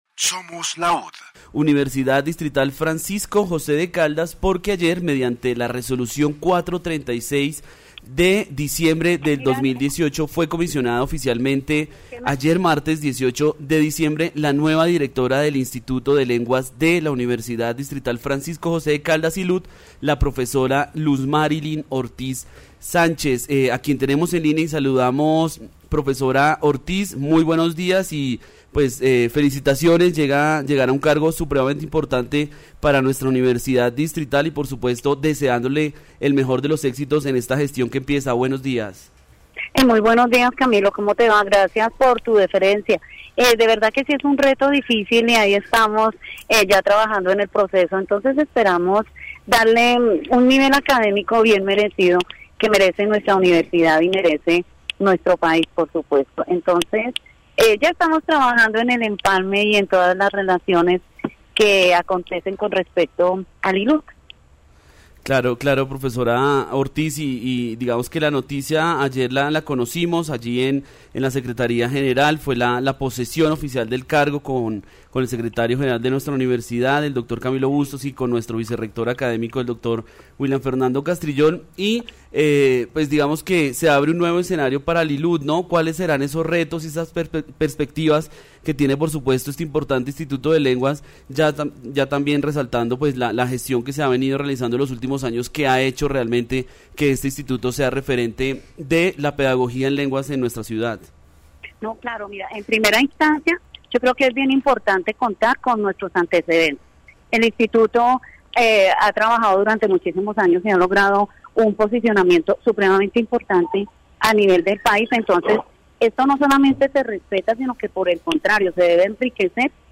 Lenguas Extranjeras, Pensamiento crítico, ILUD, Investigación, Programas de radio